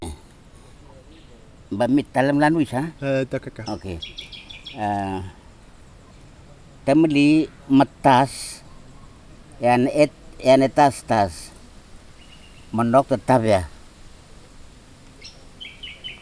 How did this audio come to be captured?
digital wav file recorded at 44.1 kHz/16 bit on Marantz PMD 620 recorder Sesivi, Ambrym, Vanuatu